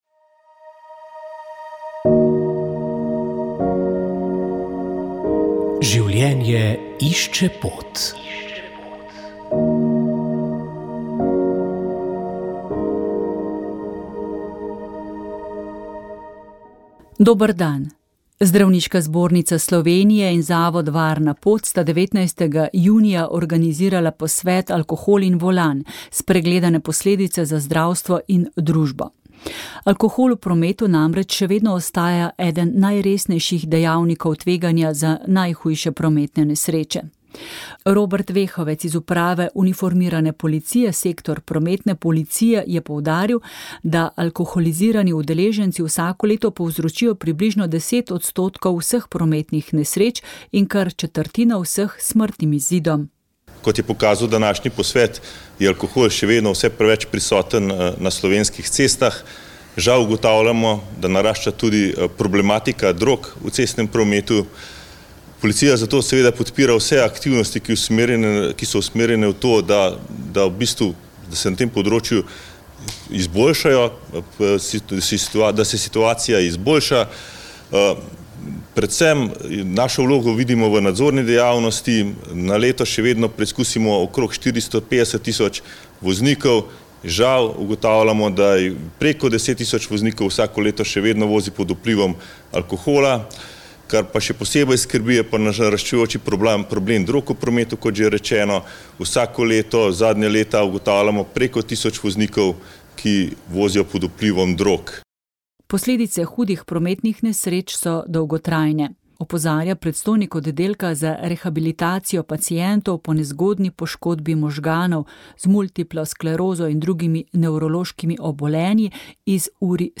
V tokratni oddaji smo se spomnili izvrstnega baritonista Toneta Kozlevčarja, ki je z žlahtnostjo glasu z lahkoto prehajal med različnimi glasbeni obdobji in celo med popolnoma različnimi glasbenimi slogi.